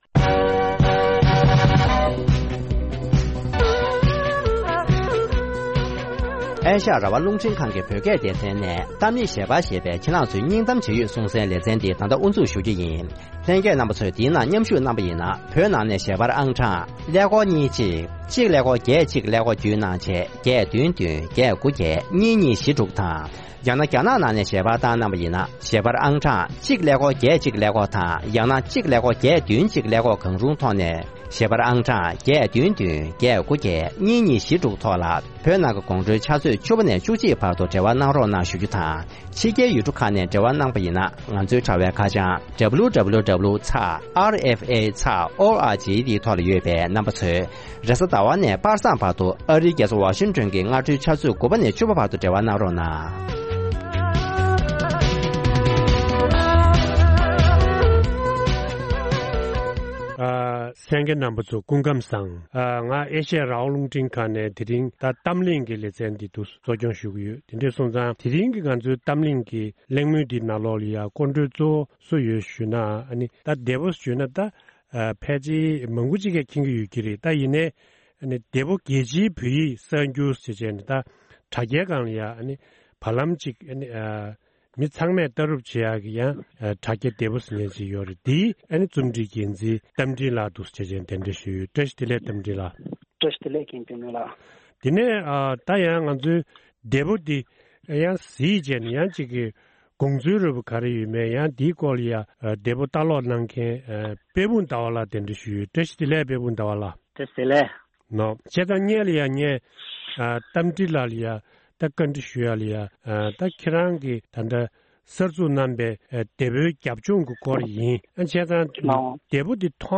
དེ་ཕོ་རྒྱལ་སྤྱིའི་བོད་ཡིག་གསར་འགྱུར་གྱི་བྱུང་རིམ་དང་དམིགས་ཡུལ་སོགས་དང་འབྲེལ་བའི་སྐོར་གླེང་མོལ།